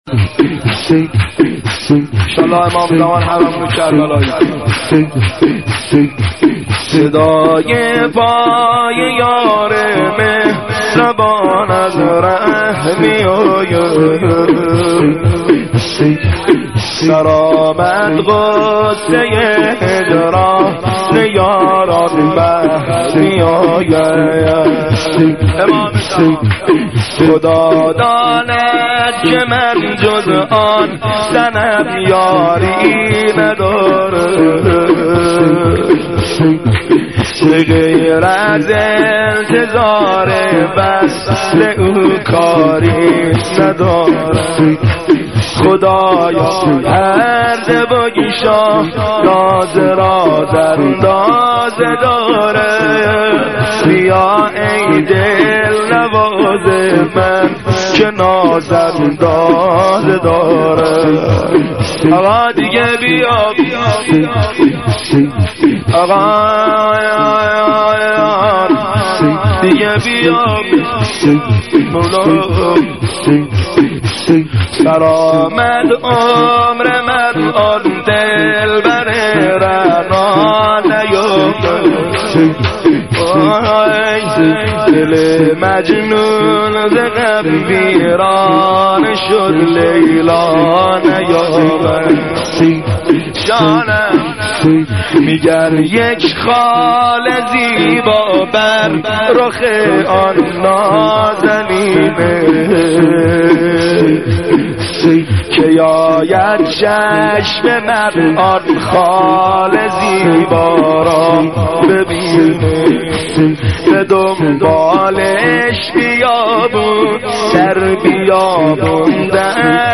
(شور - امام حسین علیه السلام)